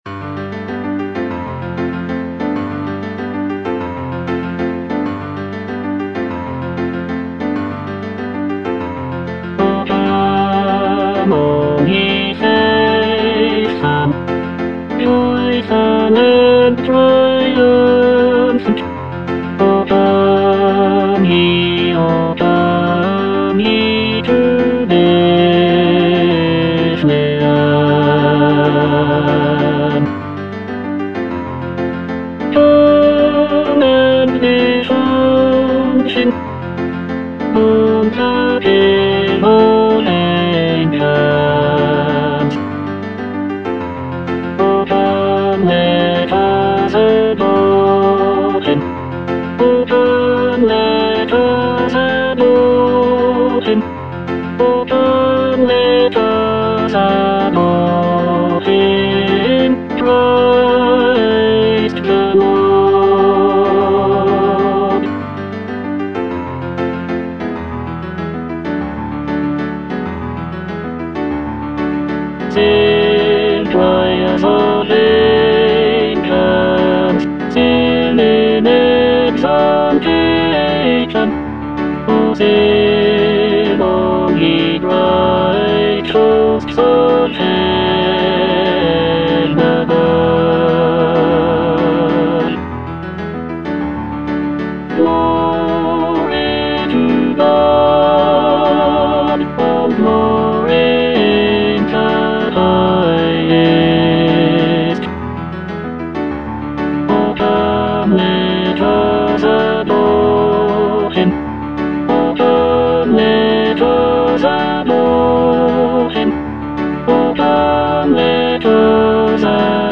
Tenor (Emphasised voice and other voices)